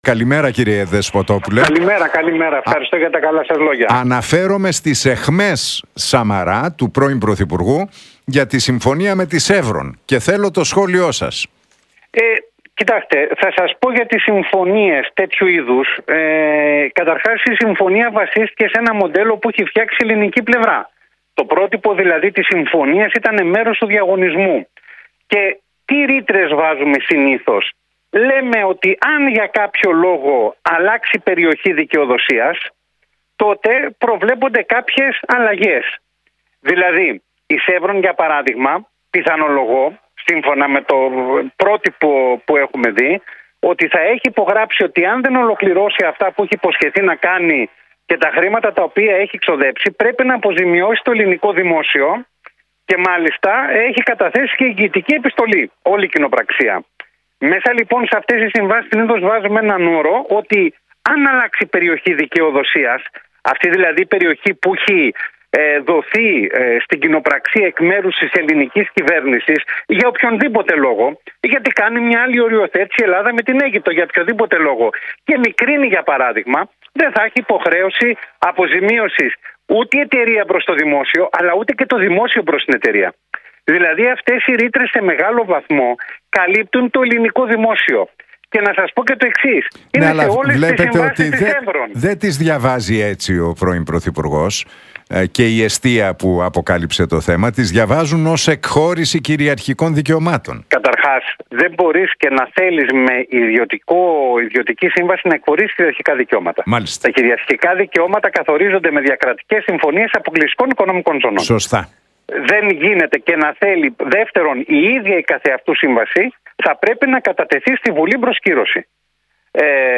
Για τις αιχμές του πρώην Πρωθυπουργού, Αντώνη Σαμαρά ότι υπάρχουν όροι στη σύμβαση με τη Chevron που αποτελούν «δυνητική εκχώρηση κυριαρχικών δικαιωμάτων» μίλησε ο διεθνολόγος